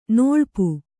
♪ nalipa